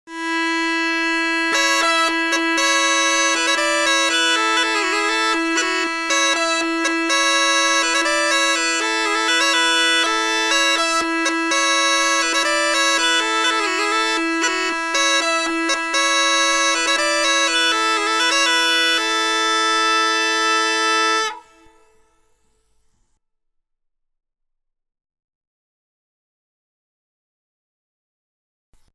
Schwedische Säckpipa:
Sein typischer, warmer und angenehmer Klang entsteht durch die zylindrische Bohrung und das Einfachrohrblatt aus Holz in der Spielpfeife.
Der Spieldruck dieses Zimmerlauten Dudelsacks ist eher gering und vergleichbar mit einem Hümmelchen.
Tonumfang: d´-e´´
Tonart: melodisch a-Moll
Bordun: d' / e' (umstimmbar)
Saeckpipa.mp3